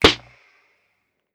Medicated Snare 12.wav